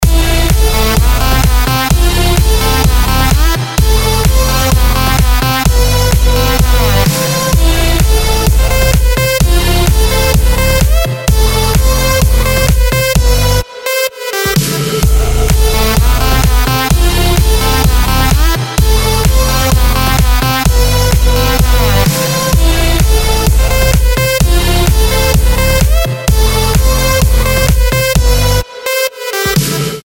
• Качество: 320, Stereo
EDM
club
electro house